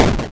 Train-of-Thought - Retro 8-Bit Game Jam — May 10th - 18th 2020 (1 week)
FallingPlatform.wav